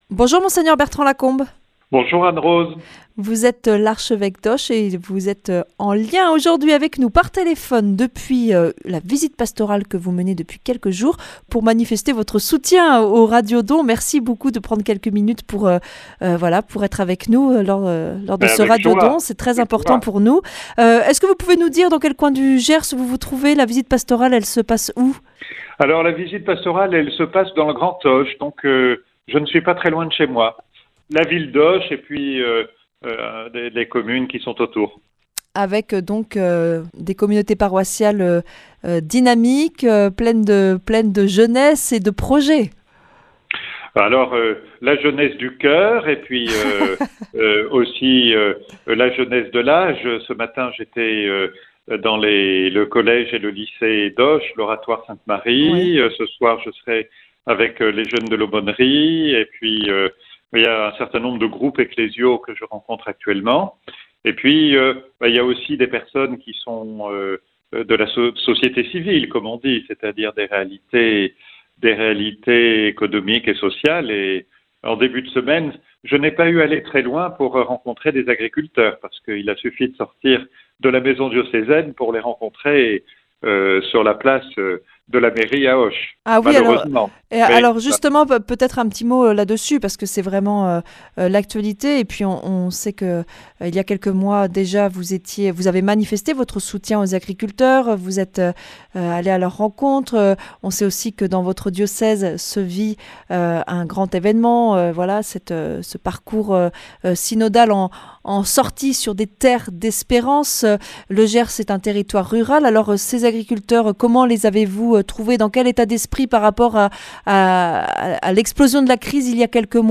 Mgr Bertrand Lacombe evêque du diocèse de Auch